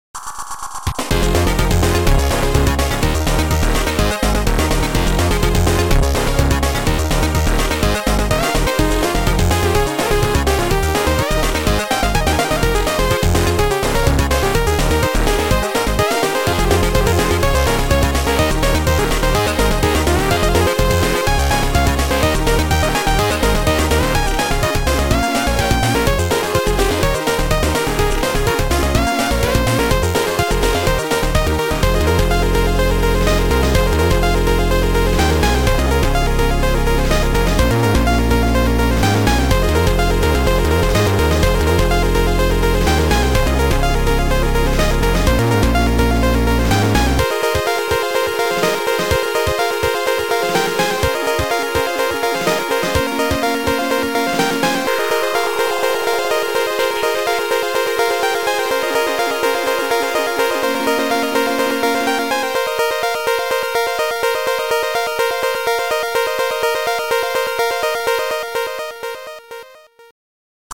Chip Music